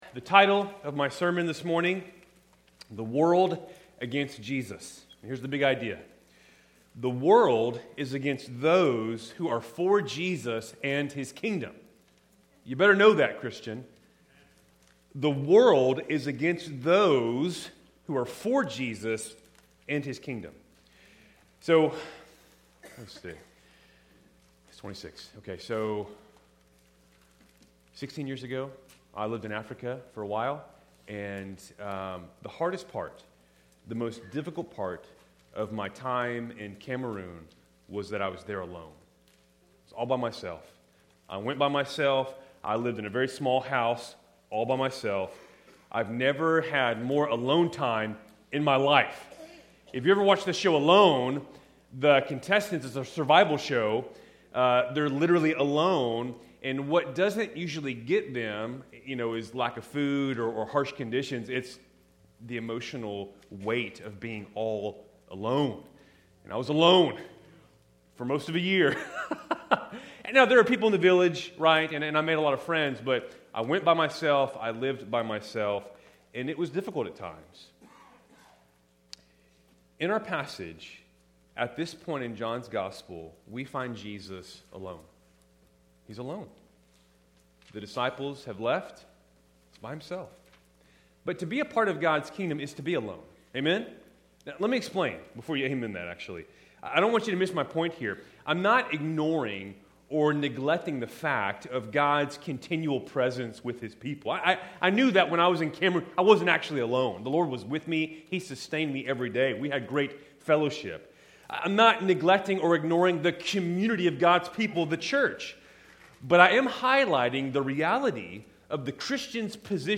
Keltys Worship Service, February 1, 2026